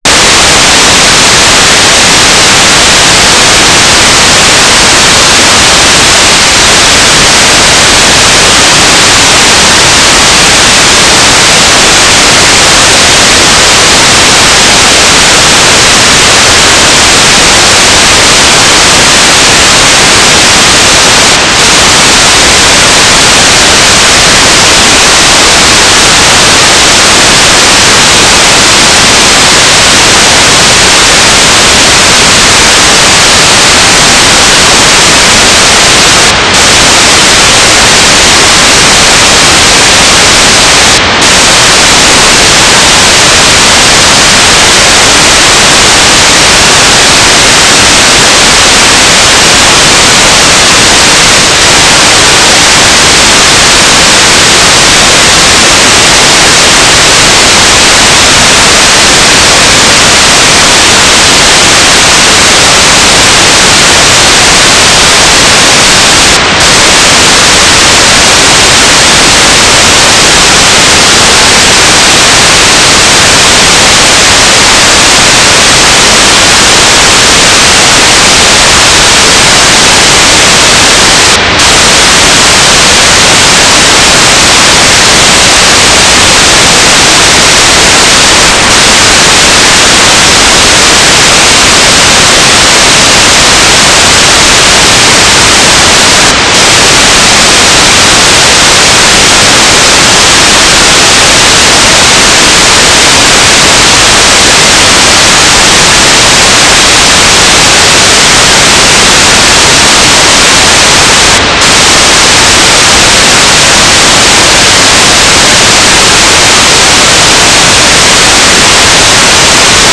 "transmitter_description": "Mode U - GFSK9k6 - AX.25",